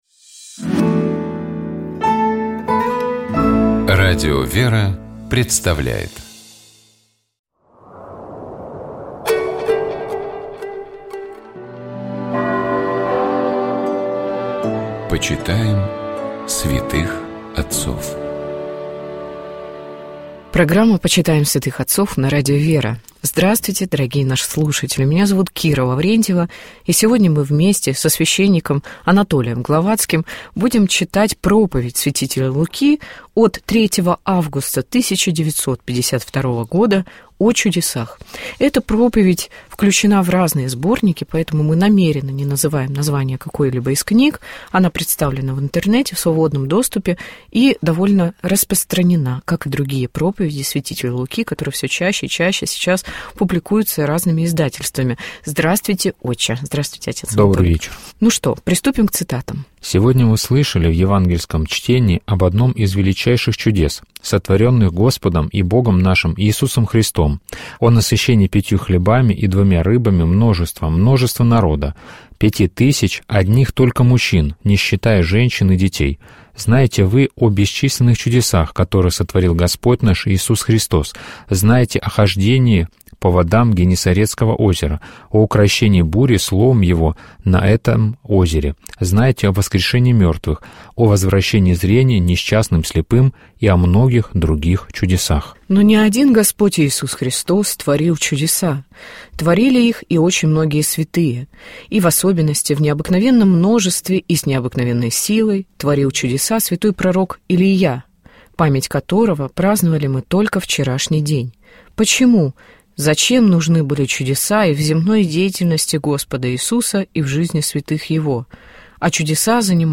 О духовном смысле праздника — епископ Тольяттинский и Жигулёвский Нестор.